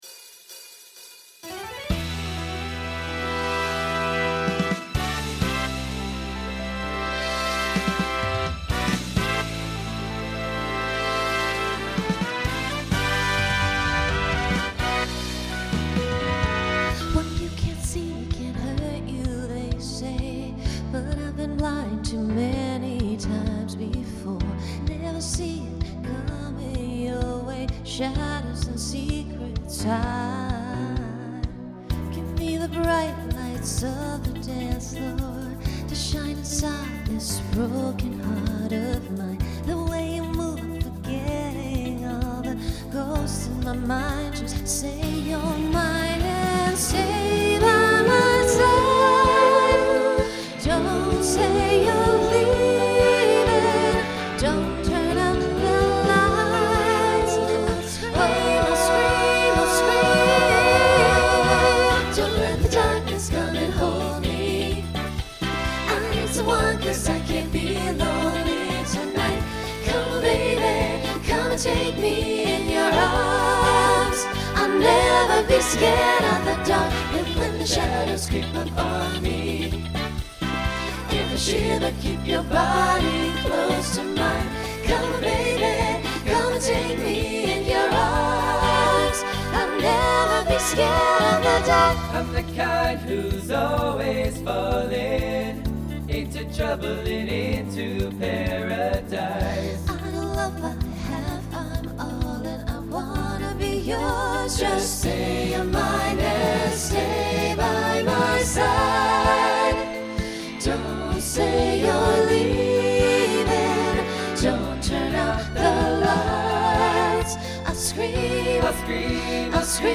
Pop/Dance Instrumental combo
Voicing SATB